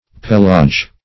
Search Result for " pellage" : The Collaborative International Dictionary of English v.0.48: Pellage \Pell"age\ (p[e^]l"[asl]j), n. [See 2d Pell .] A customs duty on skins of leather.